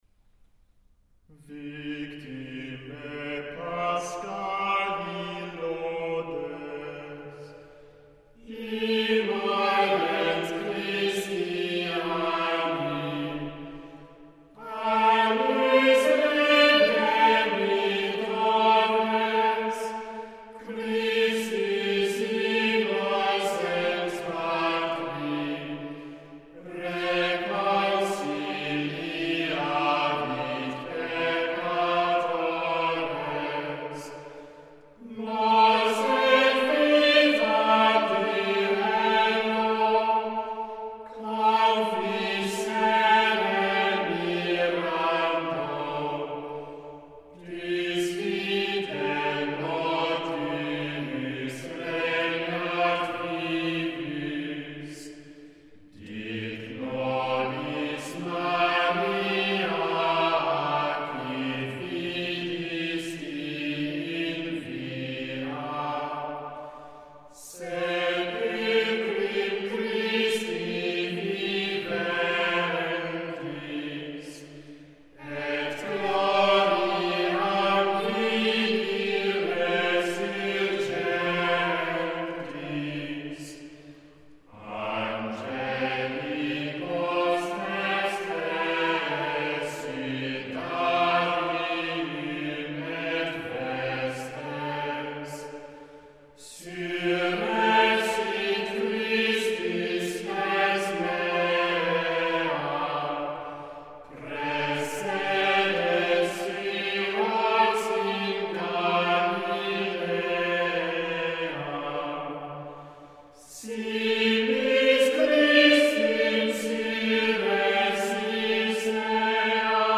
Organum
Choral [100%]
Group: A capella